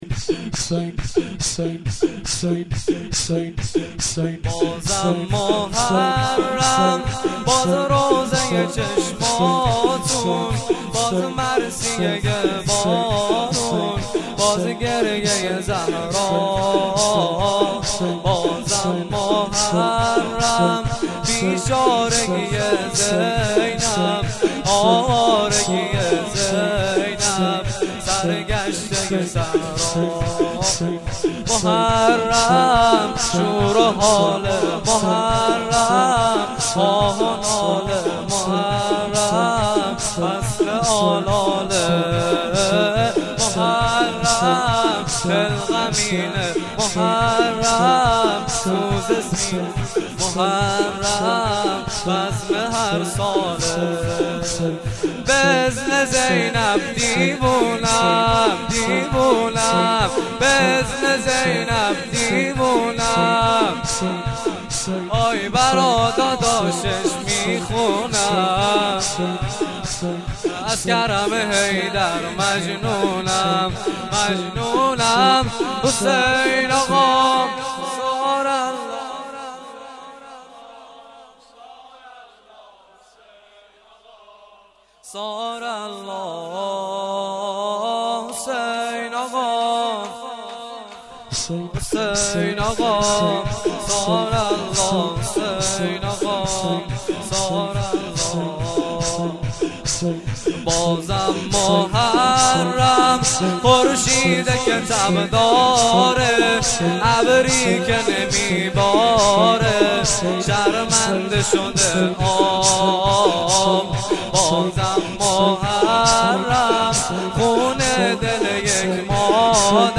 شب اول محرم
مداحی